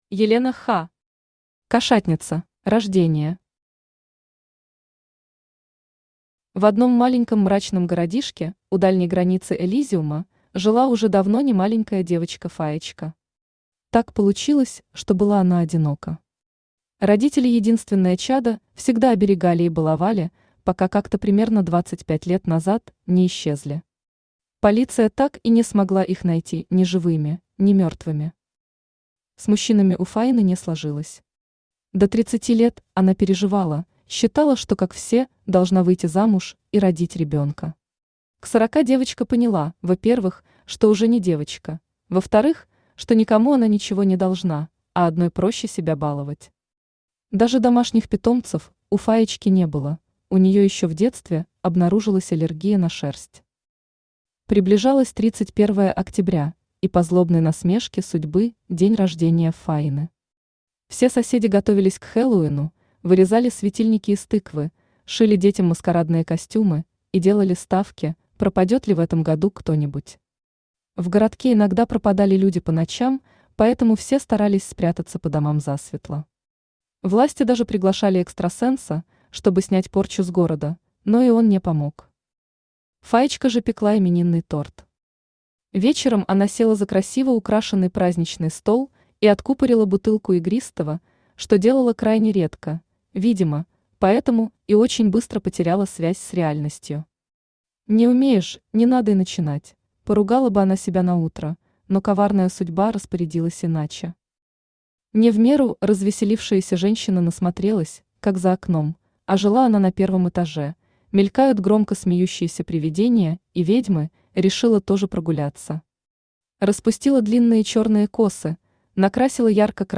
Aудиокнига Кошатница Автор Елена Ха Читает аудиокнигу Авточтец ЛитРес.